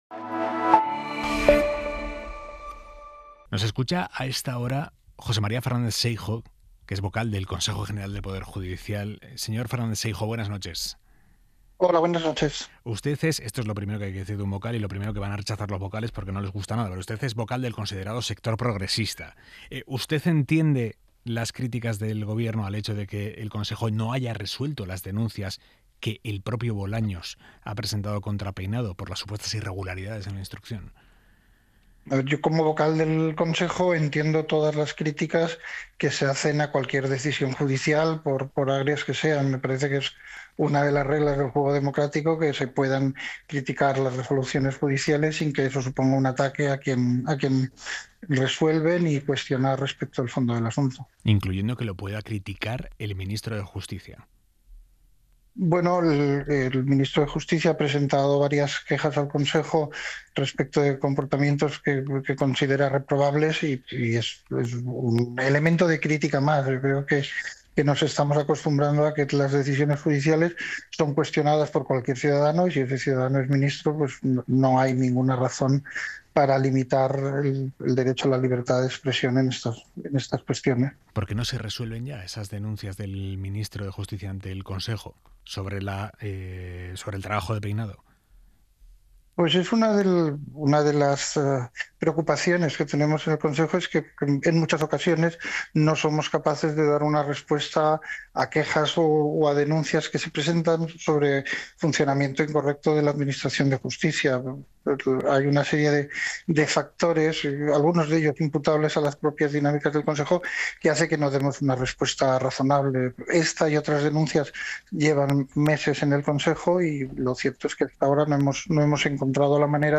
Aimar Bretos entrevista al juez José María Fernández Seijo, vocal del Consejo General del Poder Judicial